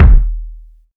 KICK.63.NEPT.wav